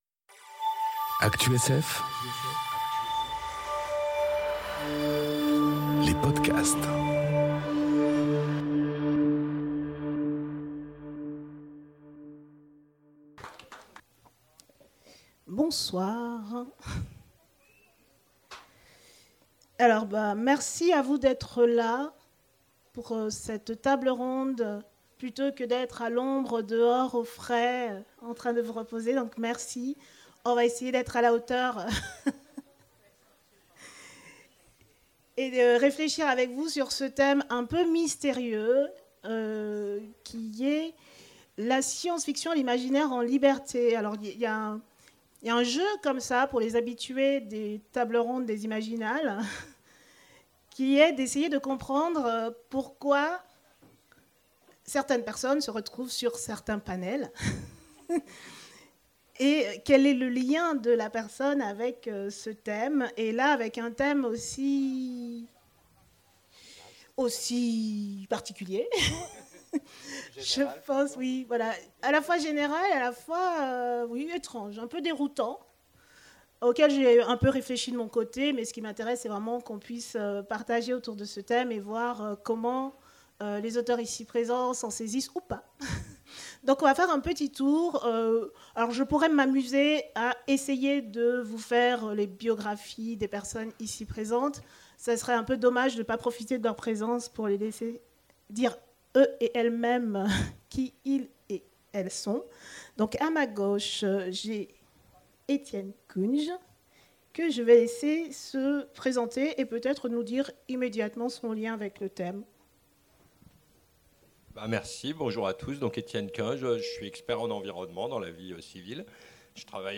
A l'occasion des Imaginales 2022, on vous propose de (ré)écoutez la table-ronde La science-fiction, c’est l’imaginaire en liberté !